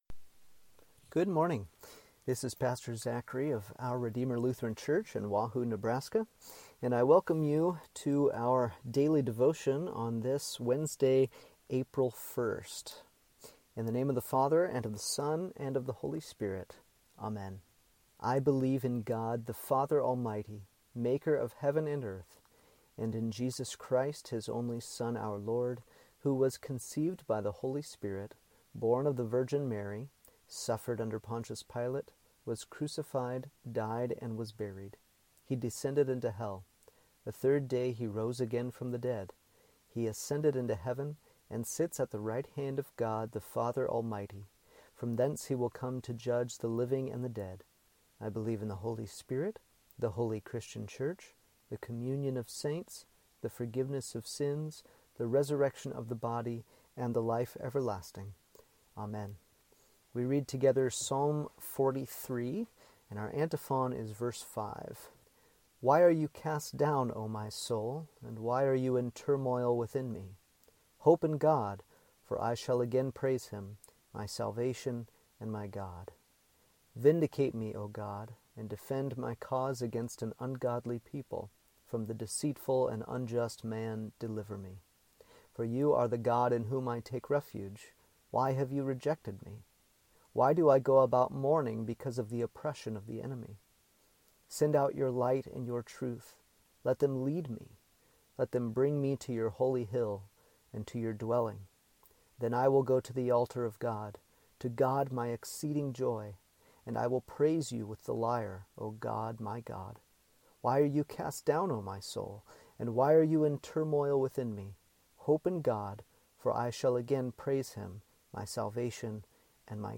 Morning Devotion for Wednesday, April 1st